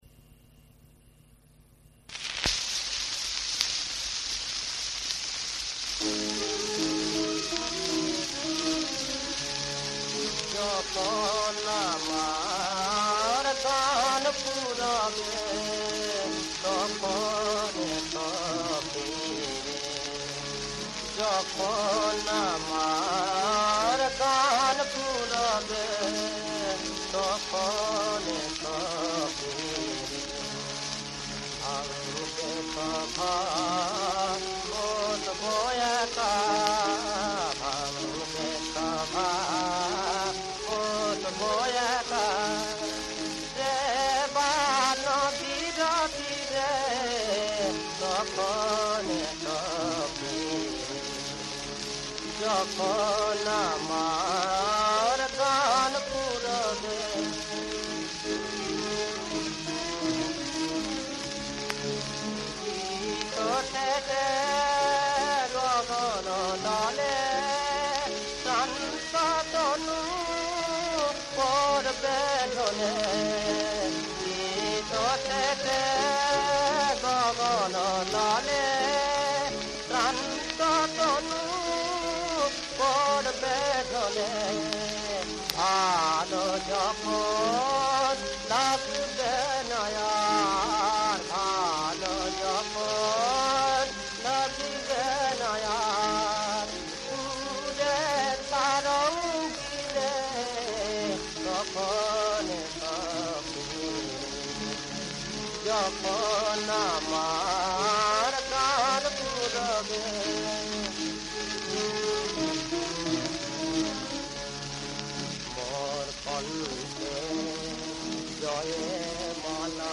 • সুরাঙ্গ: রাগাশ্রয়ী